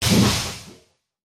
На этой странице собраны разнообразные звуки ударов и урона: резкие атаки, тяжёлые попадания, критические удары.
Звук боли – услышьте это!